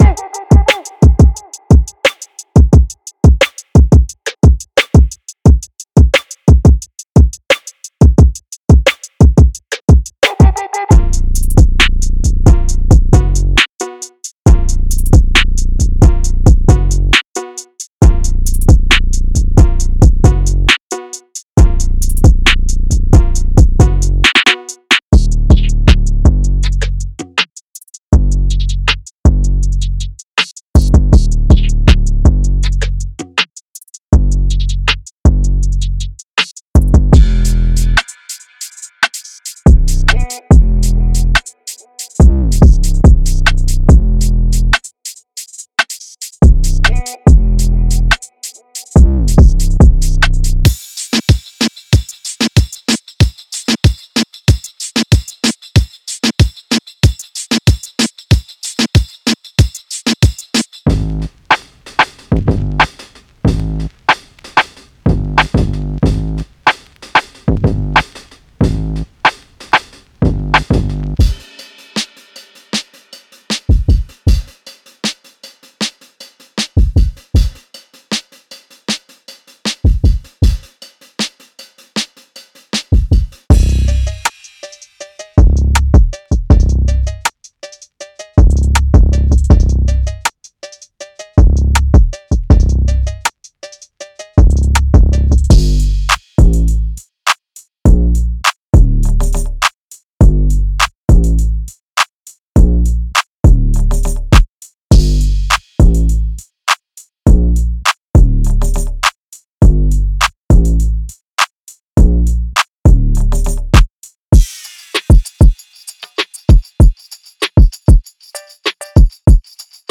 Drum Loops Demo